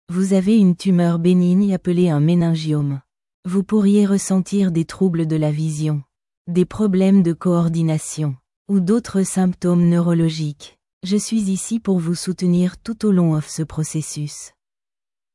text_to_speech.mp3